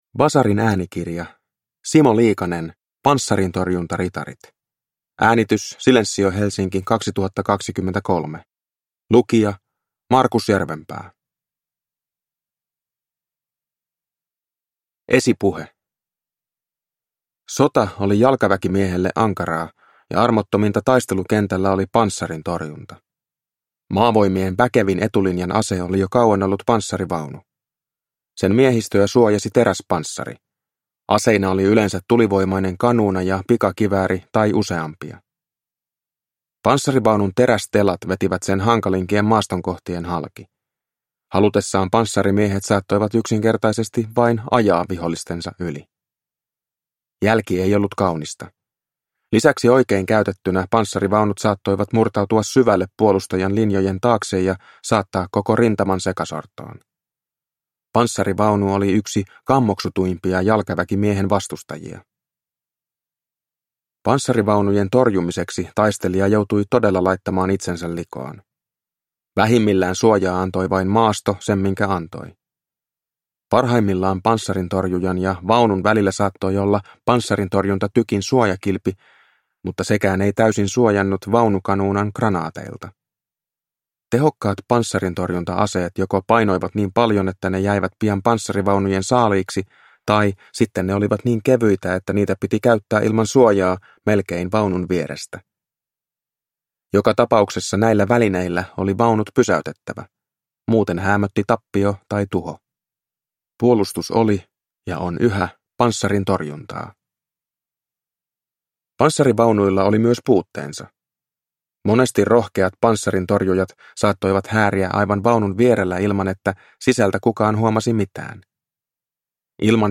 Panssarintorjuntaritarit – Ljudbok – Laddas ner